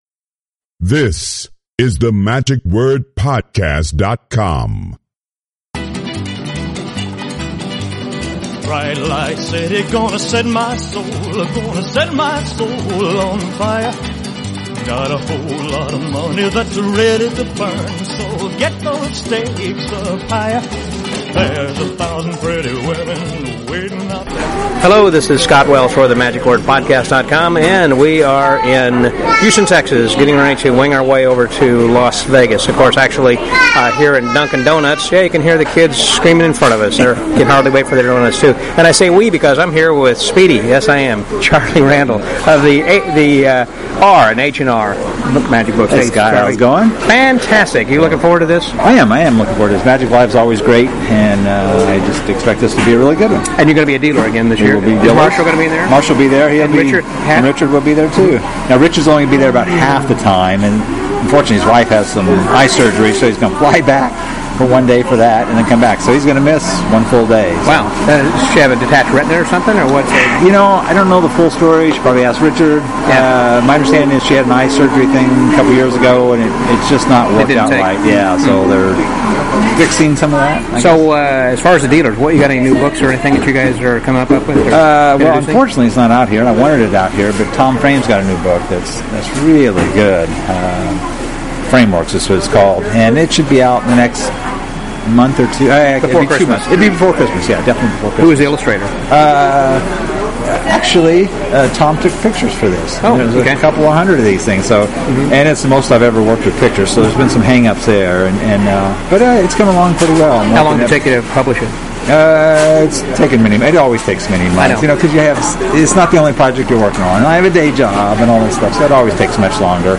For the first day of a four day event that had no scheduled activity until 7:30 p.m., there sure was a lot of meeting and greeting of old friends. In this first day update, we have some time to chat with many of the friends we ran into.